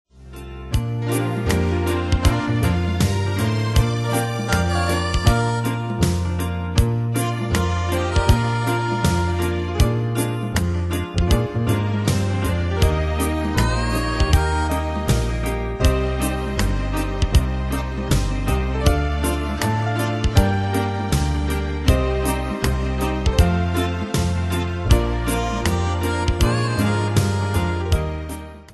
Demos Midi Audio
Pro Backing Tracks